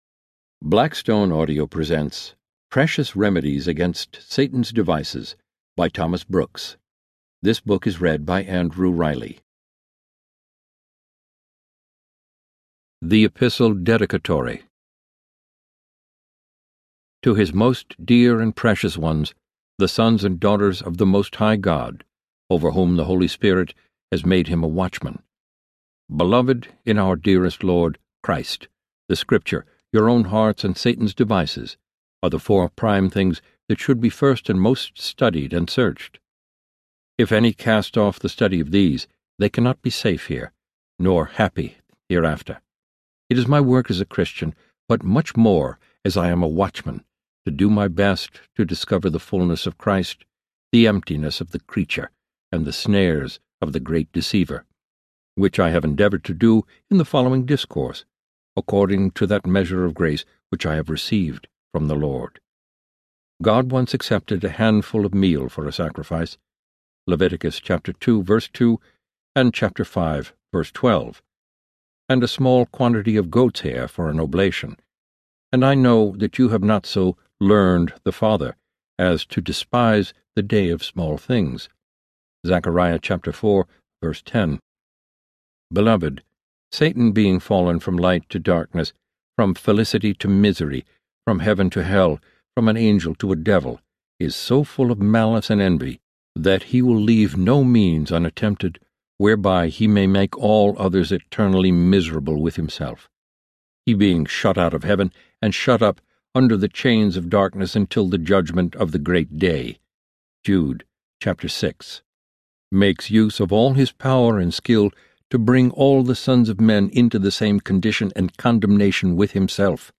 Precious Remedies against Satan’s Devices Audiobook
Narrator
10.3 Hrs. – Unabridged